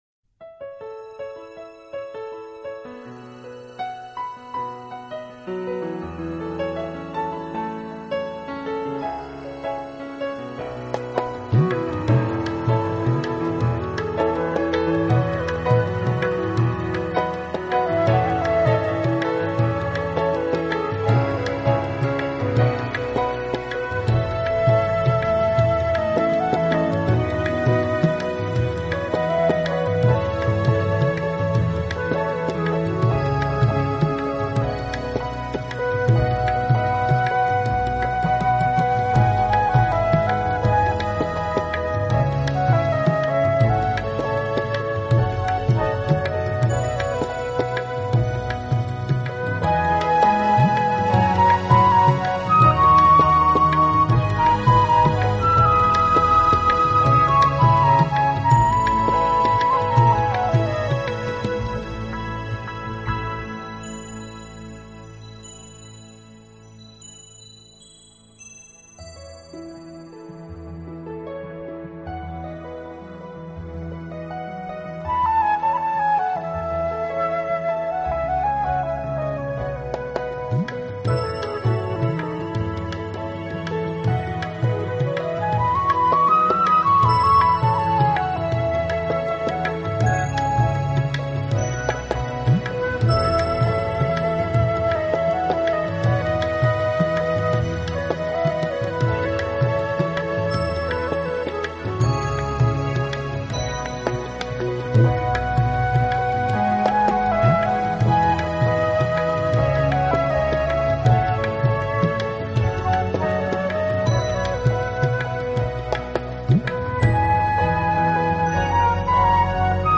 1991 类型: Relaxation, New Age 资源